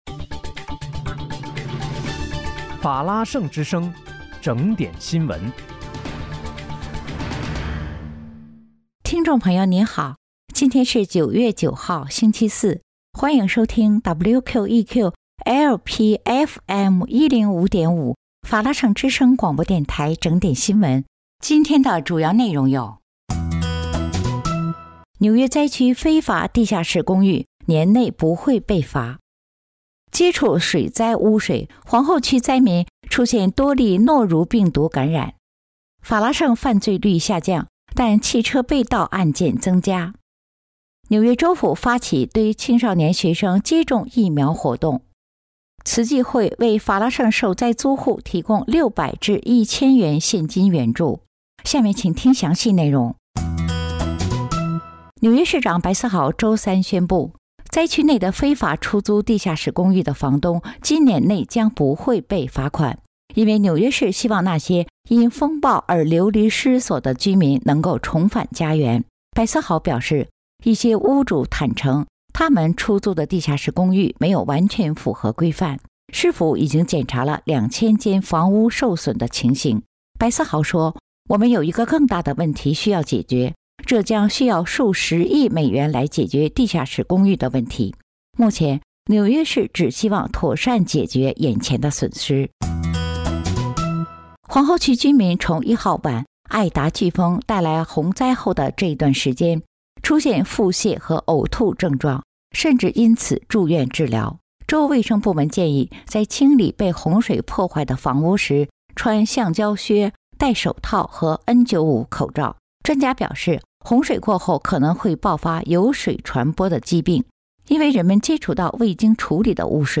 9月9日（星期四）纽约整点新闻